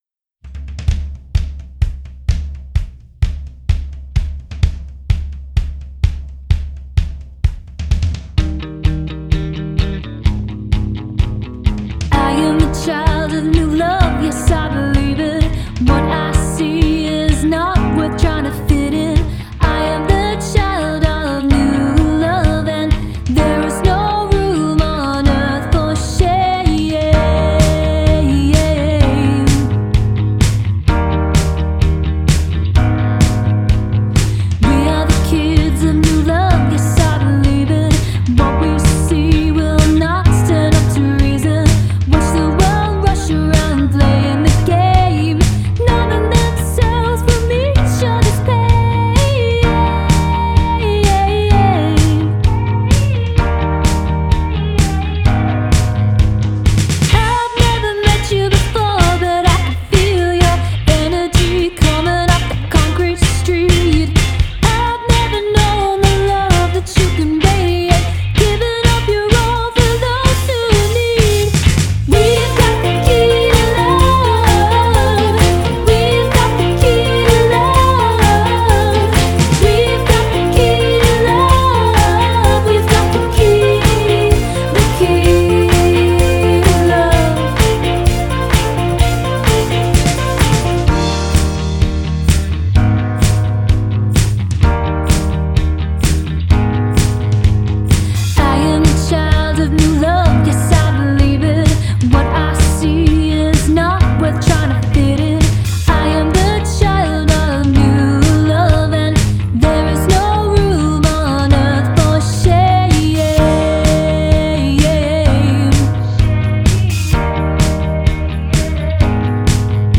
Vocals
Drums and Percussion
Bass, Guitar, Piano and Keys
Synths, Guitar and Percussion
Recorded at Studios 301, Sydney